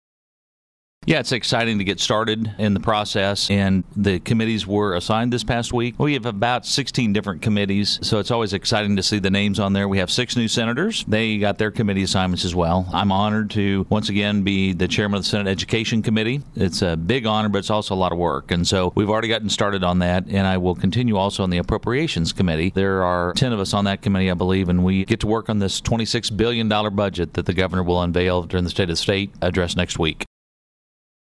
The following audio comes from the above interview with Sen. Pearce, for the week of Jan. 12, 2015.